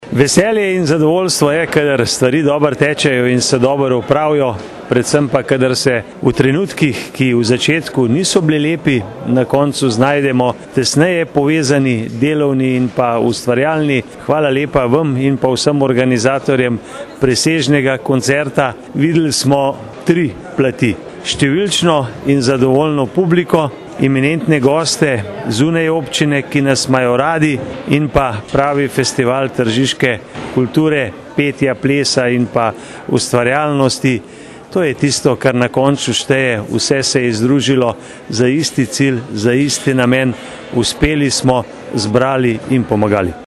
izjava_mag.borutsajoviczupanobcinetrzicpokoncertustopimoskupaj.mp3 (1,0MB)